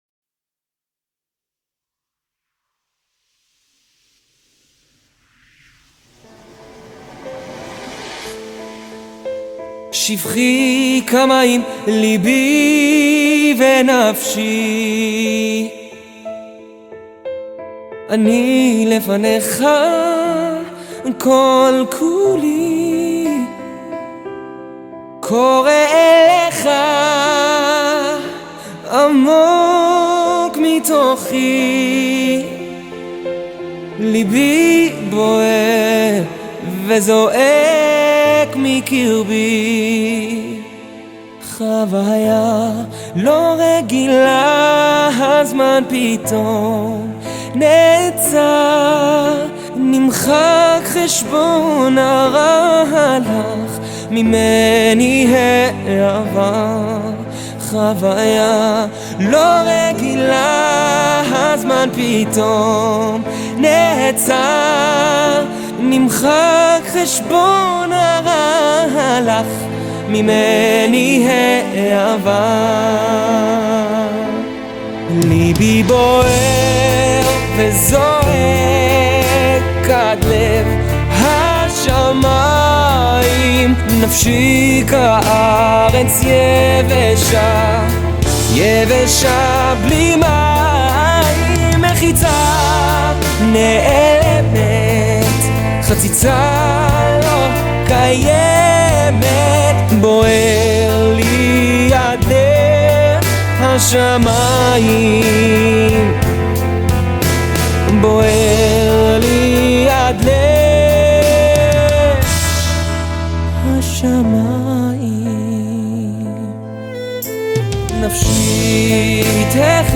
מרגיע ומלטף, הלחן יושב על השיר ועל הקול שלך מצוין.
והקול שלך מקסים